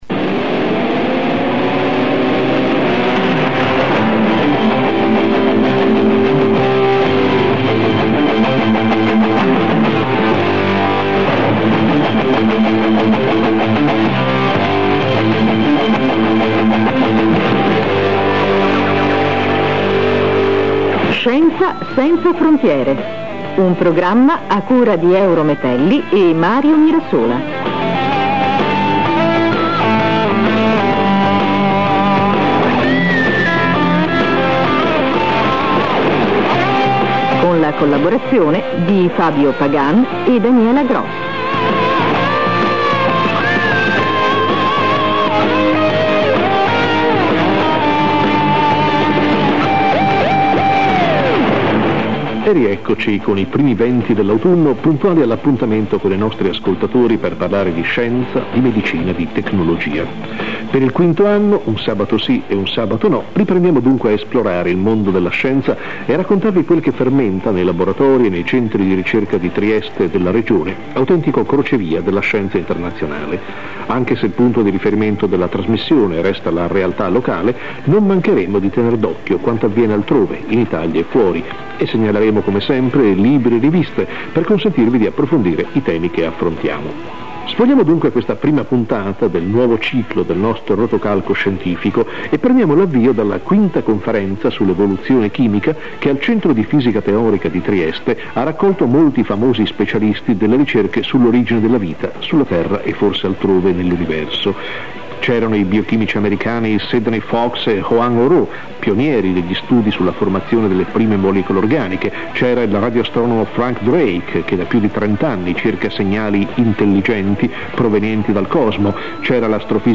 Radio 3 Rai (the Italian Network ): " Scienza senza Frontiere", transmitted on 11 October 1997. The occasion was the Fifth Conference on Chemical Evolution that was organized in Trieste.
Opposite views are expressed on the likelyhood of finding biosignatures in the solar system (specifically on Europa, the Jovian Moon ). Interviews 1 (mp3) (12 minutes) Interviews 2(rm) (12 minutes).